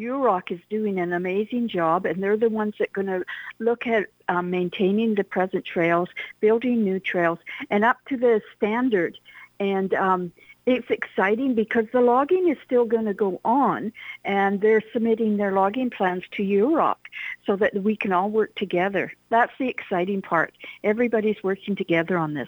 Mayor Leslie Baird says it’s a great partnership.